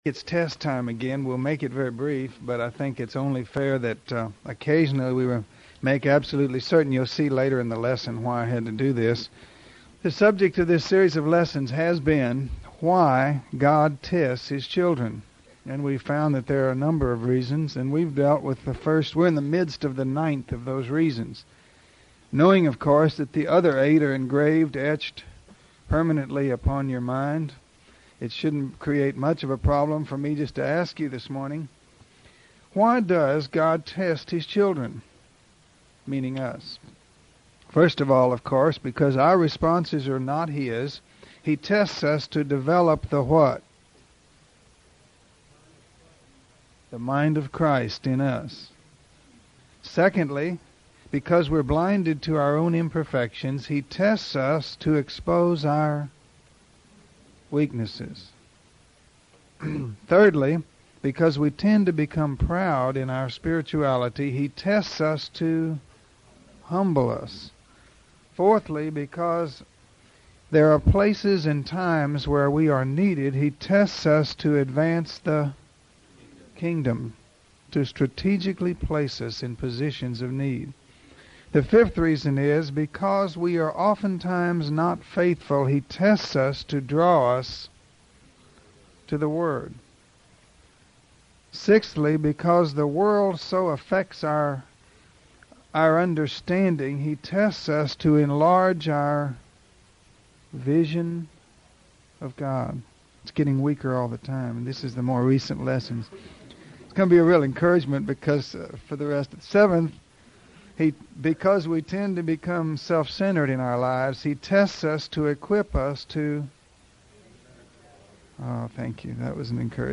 In this lesson, we will see how Satan attempts to steal the seed that the sower has sown.